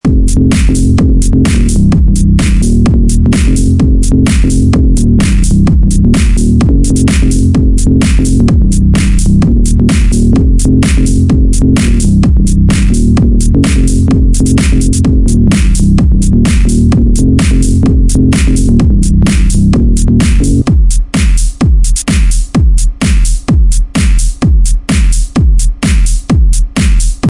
Download Club Party sound effect for free.